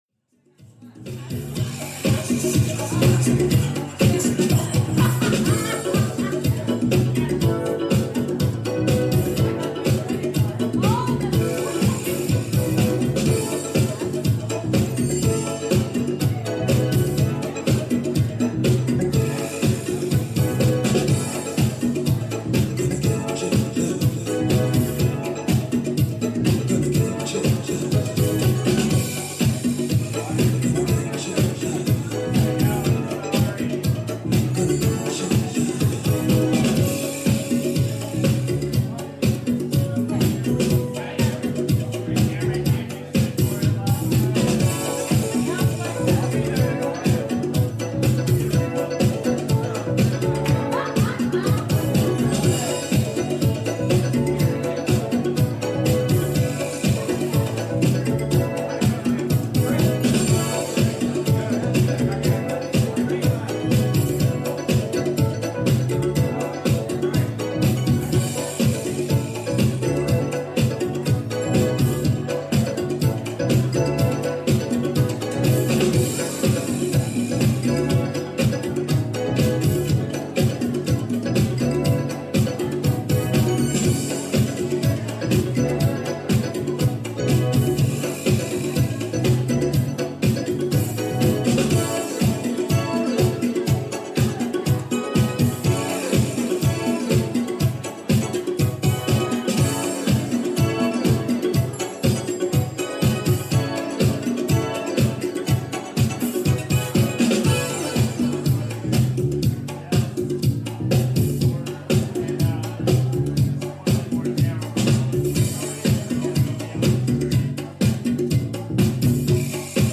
: Apr 22, 2018: 7pm - 8pm Live remote stream from WGXC broadcast partner HiL... Recorded from a live webstream.